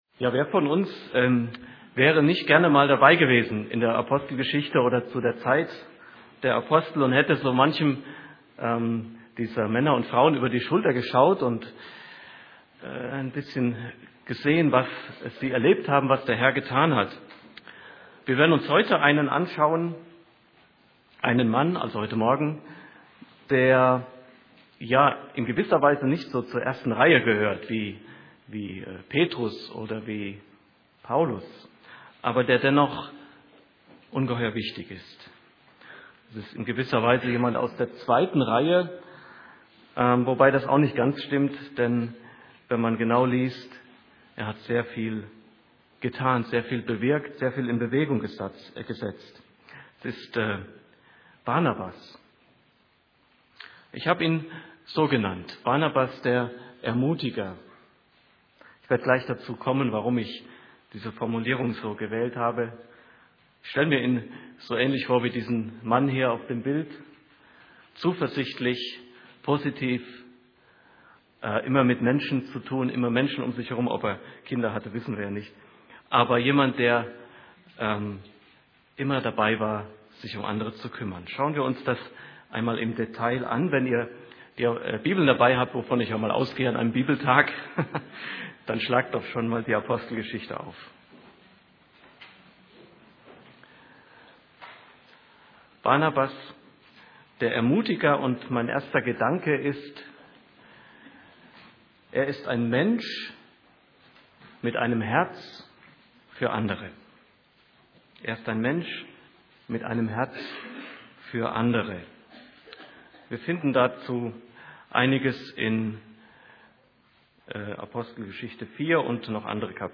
:: 12. Rosenheimer Bibeltag 2009 | Homepage
Vortrag 1: Barnabas, der Ermutiger